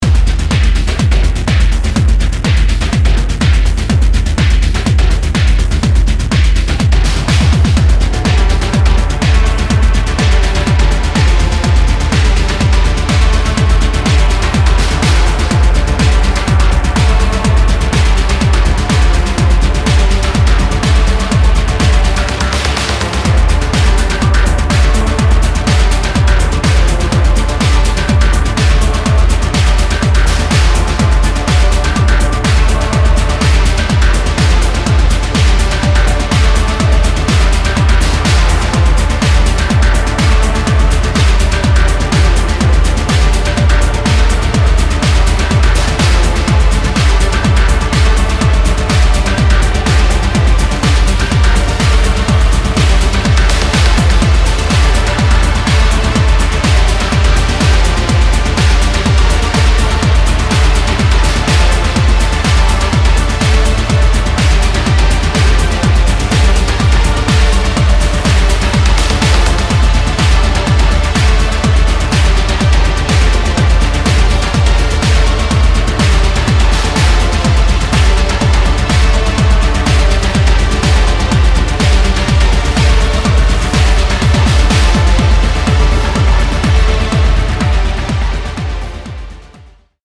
EBM , Techno